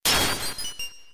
snd_mirrorbreak.wav